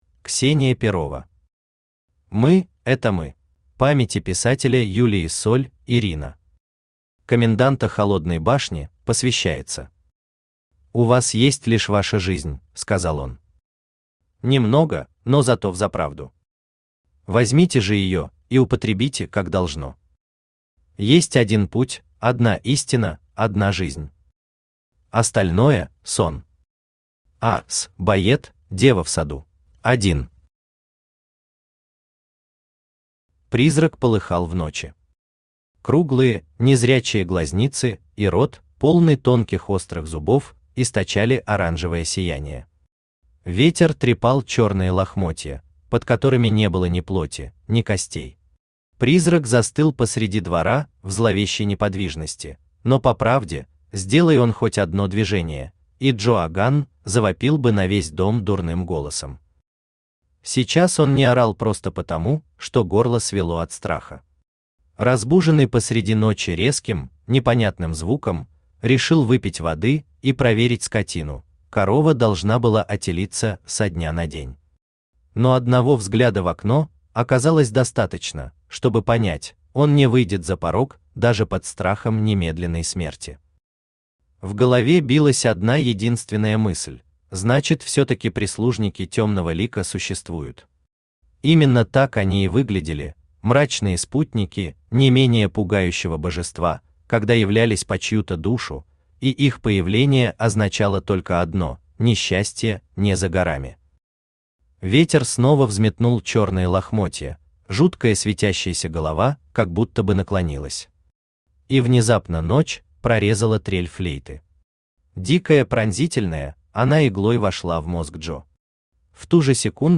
Аудиокнига Мы – это мы | Библиотека аудиокниг
Aудиокнига Мы – это мы Автор Ксения Перова Читает аудиокнигу Авточтец ЛитРес.